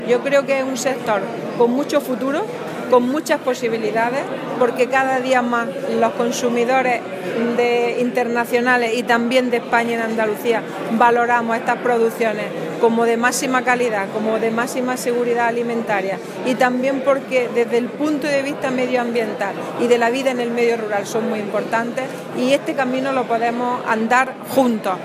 Carmen Ortiz ha pronunciado estas palabras en Sevilla durante la clausura de la cuarta edición de la Conferencia Europea sobre elaboración de productos ecológicos, organizada por primera vez en España por la asociación europea de la Federación Internacional de la Agricultura Ecológica (Ifoam-UE) y la Asociación Valor Ecológico-Ecovalia.
Declaraciones consejera sobre sector